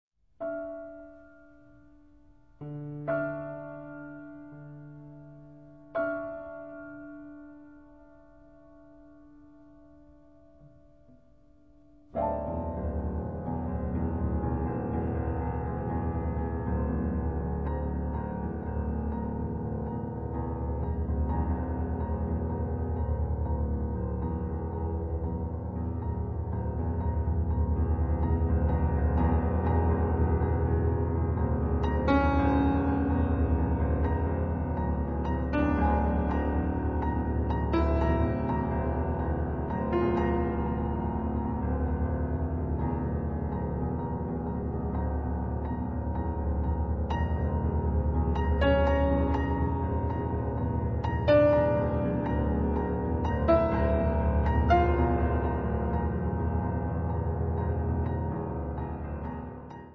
pianoforte
fatta di spesse tessiture armoniche, energia pura